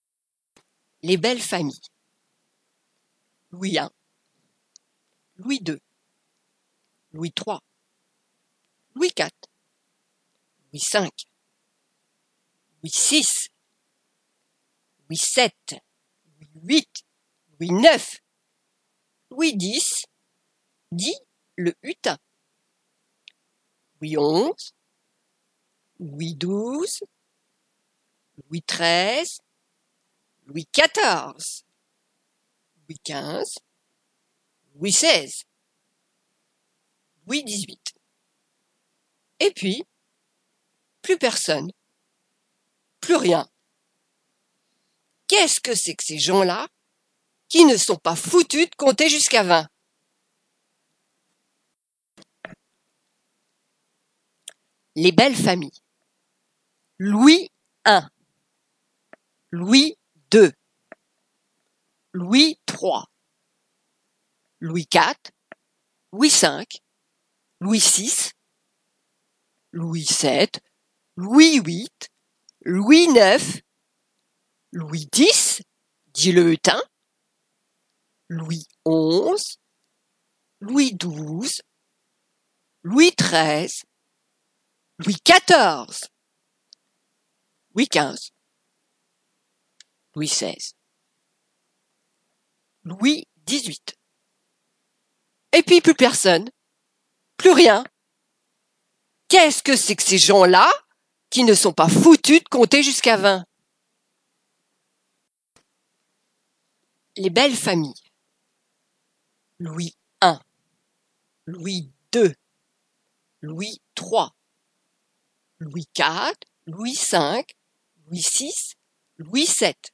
Proposé par l'atelier théâtre adultes